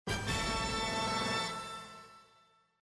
minislot_multiple_rewards.mp3